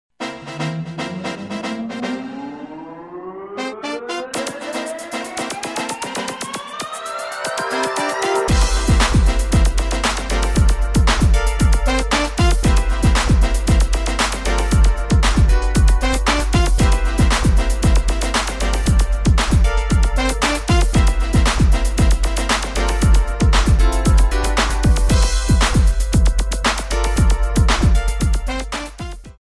10 Music tracks in various dance styles.
Warm-up, Cool Down, Modern, Jazz, Hip Hop